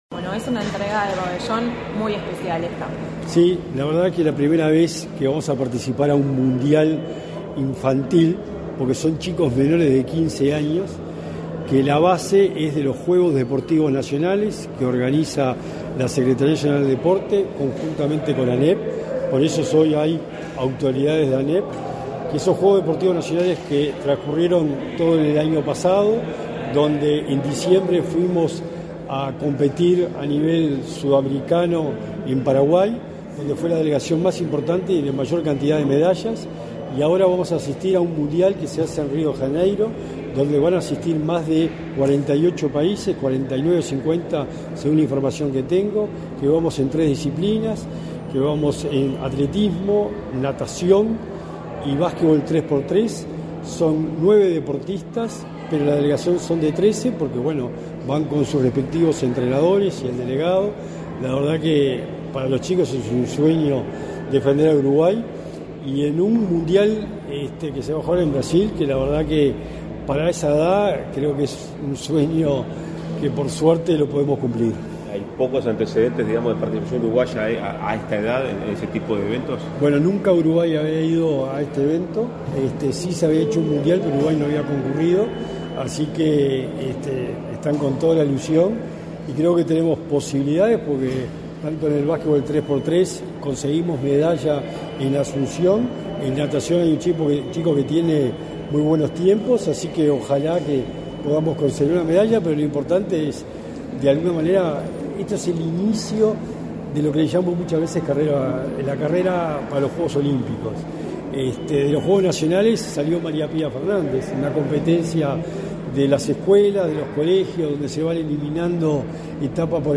Declaraciones del secretario nacional del Deporte, Sebastián Bauzá
Tras el evento, su titular, Sebastián Bauzá, realizó declaraciones a la prensa.